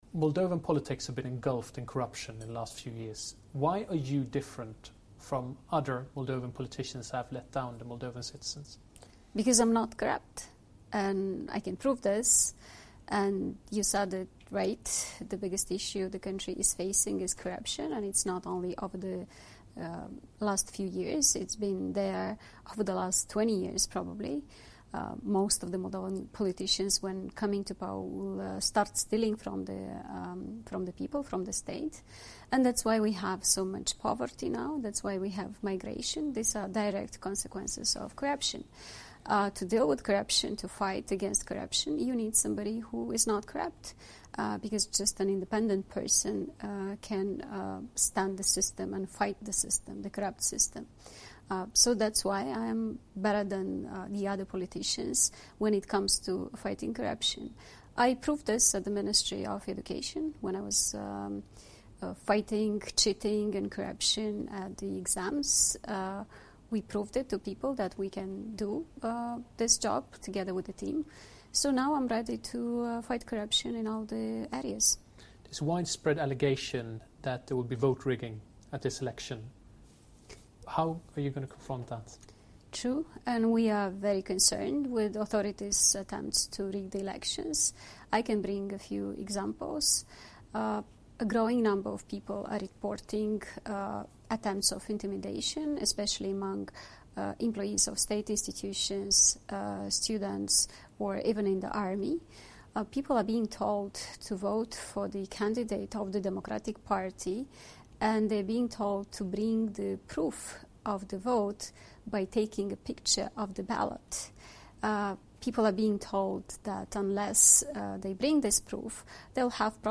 Maia Sandu, candidada PAS, PPDA si PLDM la alegerile prezidenţiale din Moldova din 30 octombrie, se află la Bruxelles şi, într-un interviu cu corespondentul nostru, şi-a exprimat temerile că alegerile de la sfirsitul lunii ar putea să fie grav afectate de comportamentul Partidului Democrat, care ar intimida alegatorii si ar intenţiona fraudarea alegerilor.